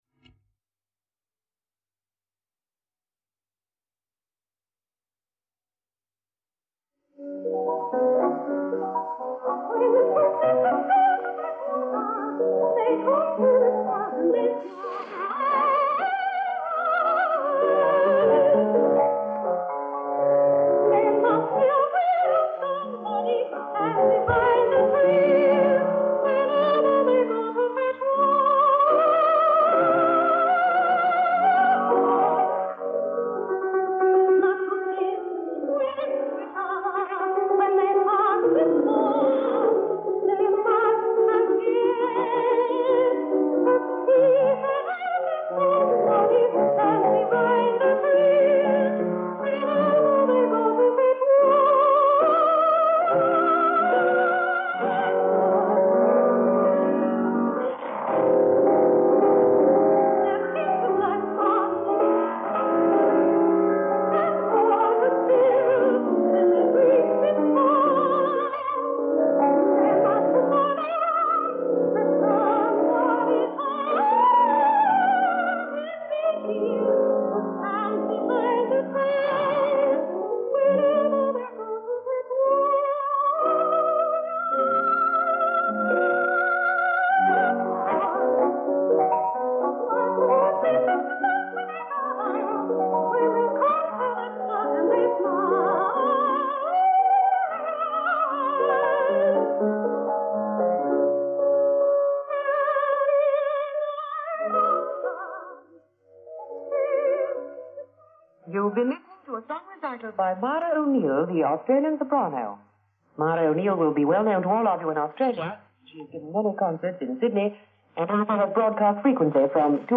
who took her lovely soprano voice to Italy in June to see what could ‘be done with it.
Naturally the quality of the tape is poor
a lyric soprano